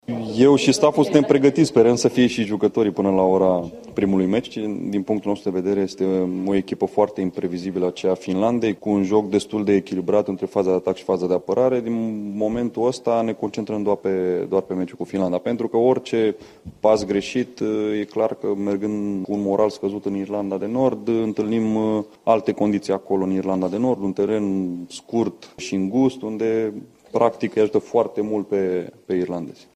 Duelul cu nordicii, care au un punct și un meci în plus față de tricolorii mici, are loc la Voluntari și e prefațată de selecționerul Mirel Rădoi:
Mirel-Radoi-despre-Finlanda.mp3